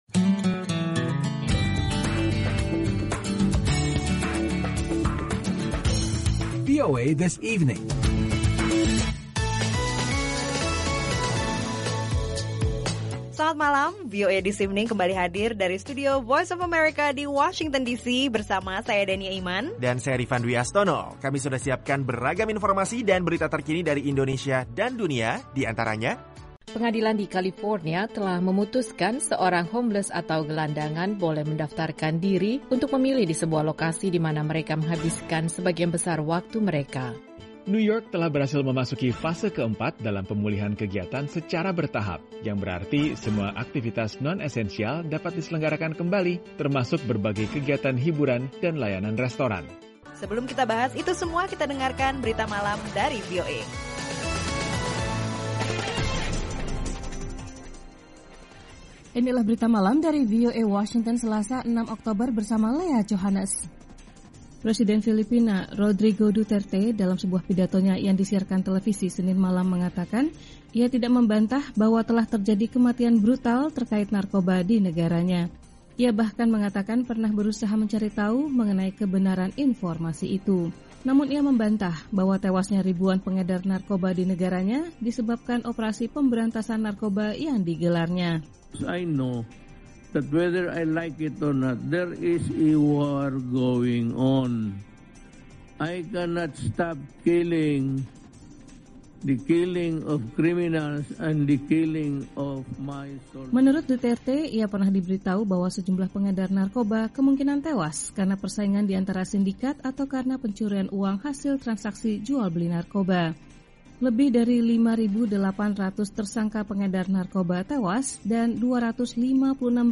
Akhiri kesibukan hari kerja Anda dengan rangkuman berita terpenting dan informasi menarik yang memperkaya wawasan Anda dalam VOA This Evening.